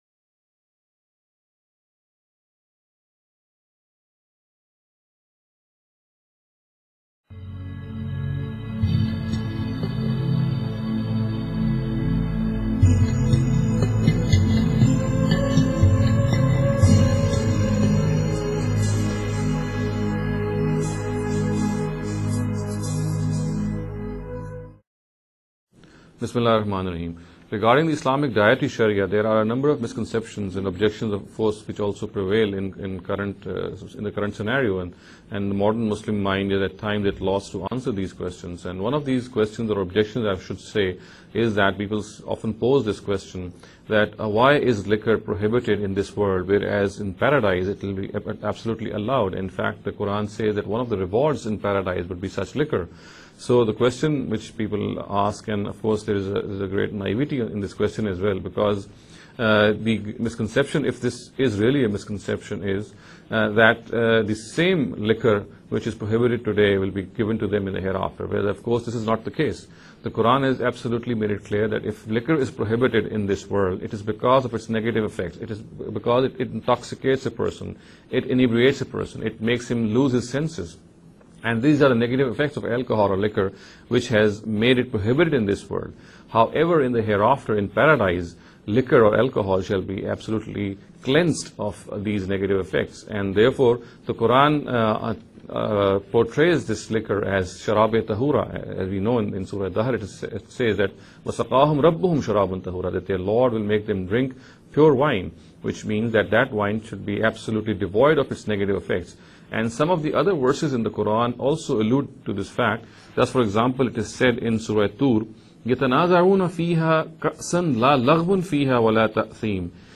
This lecture series will deal with some misconception regarding The Dietary Directives of Islam. In every lecture he will be dealing with a question in a short and very concise manner. This sitting is an attempt to deal with the question 'Why is Wine allowed in Paradise?’.